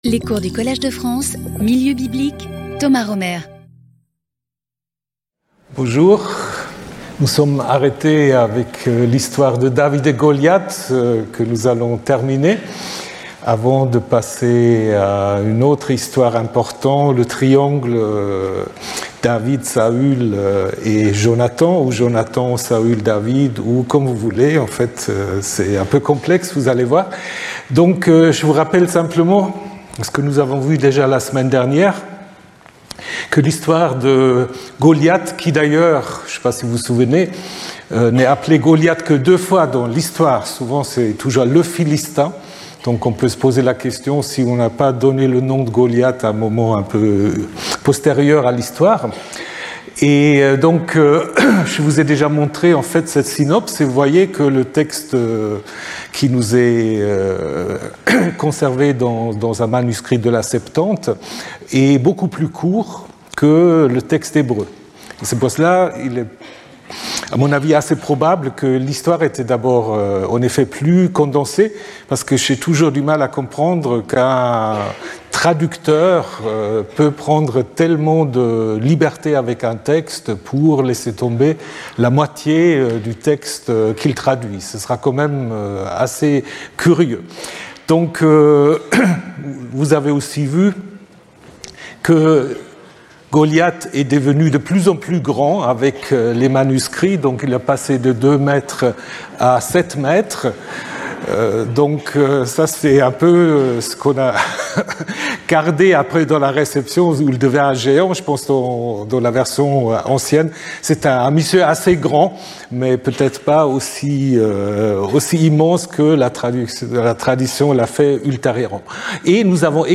Intervenant(s) Thomas Römer Professeur et administrateur du Collège de France Événements Précédent Cours 12 Fév 2026 14:00 à 15:00 Thomas Römer Saül, David, Salomon : figures mythiques ou historiques ?